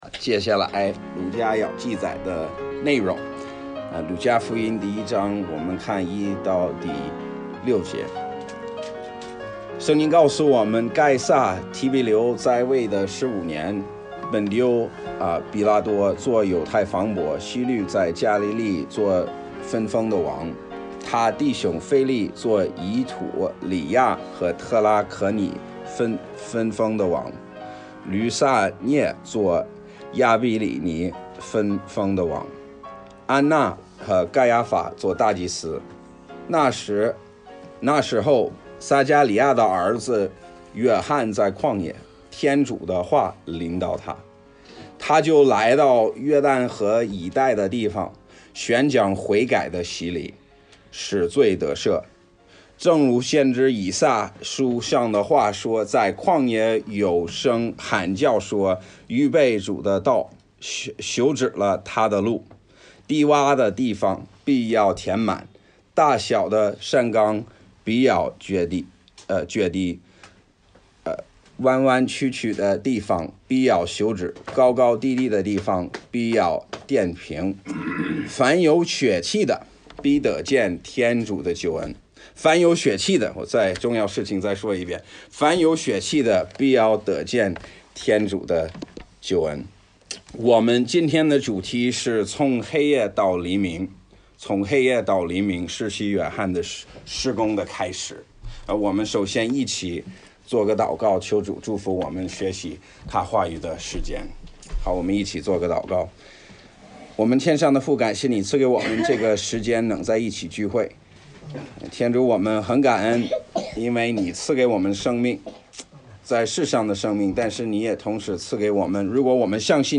讲道 – 哈尔滨权胜浸信教会